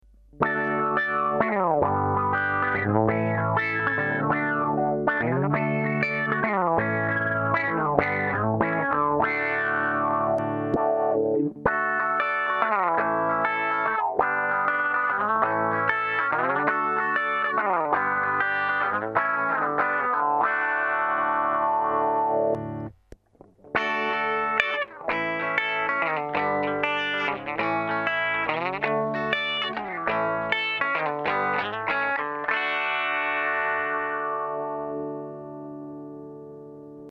En los tres ficheros he repetido el mismo riff (más o menos) usando primero mi clon del EH Doctor Q (versión Improved), mi clon del Mutron Micro V (idéntico al original) y mi clon del Mutro Micro V más antiguo (con la variante del condensador C4 de 10K en lugar de los 33K del circuito original) Para saber más acerca de este montaje: Mu-Tron Micro V. Archivo en Mu-Tron Micro V casero.